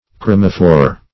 Chromophore \Chro"mo*phore\, n. [Gr.